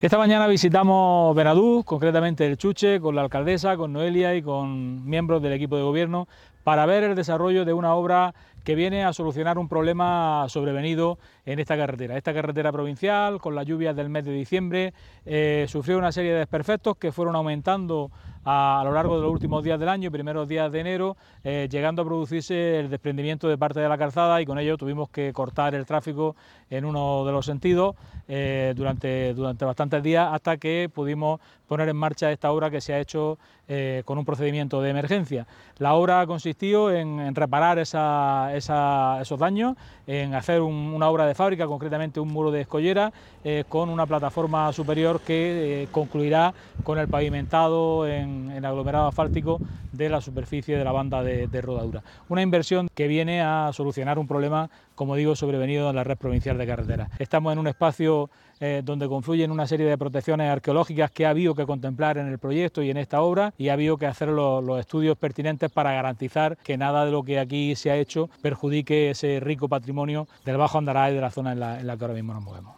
23-04_chuche_diputado.mp3.mp3